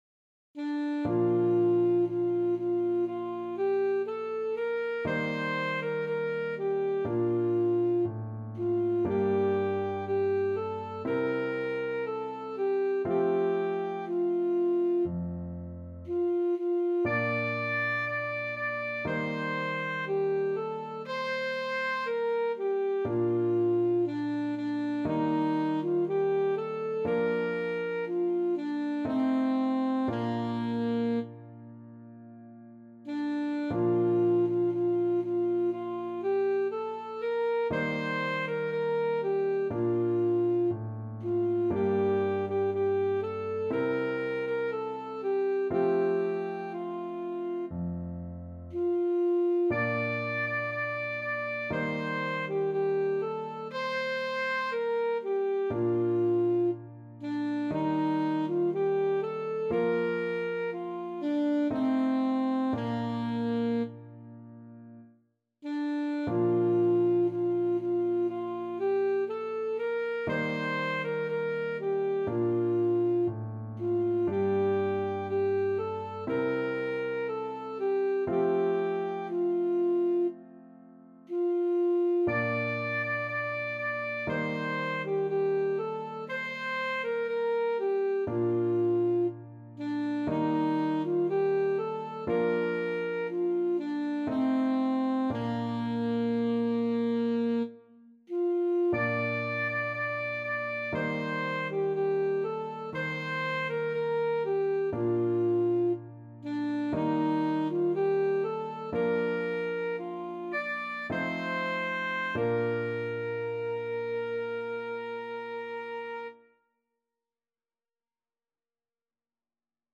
Classical Merikanto, Oskar Dar bjorkarna susa Alto Saxophone version
Alto Saxophone
Bb major (Sounding Pitch) G major (Alto Saxophone in Eb) (View more Bb major Music for Saxophone )
~ = 100 Adagio
4/4 (View more 4/4 Music)
Bb4-D6
Classical (View more Classical Saxophone Music)